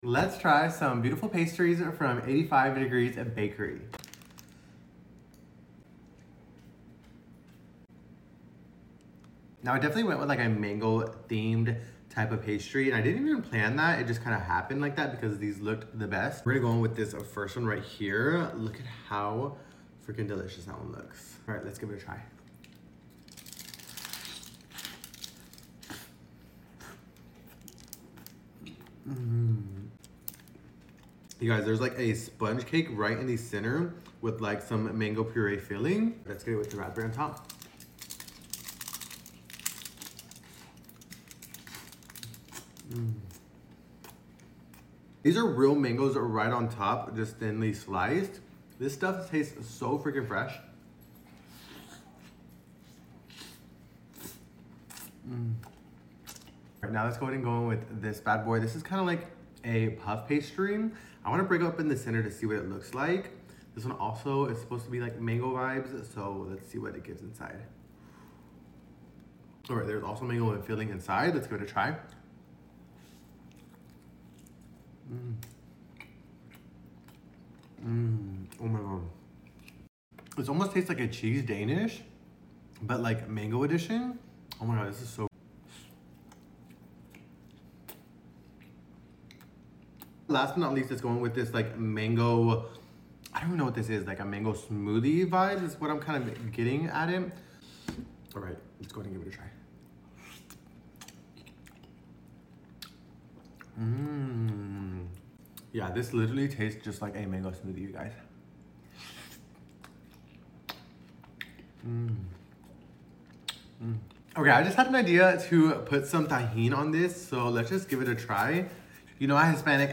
85°c bakery mukbang!! these beautiful sound effects free download